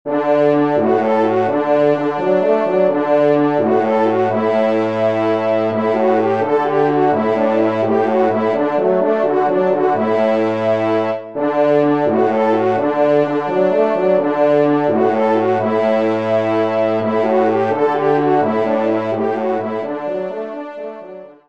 Genre :  Divertissement pour Trompes ou Cors
4ème Trompe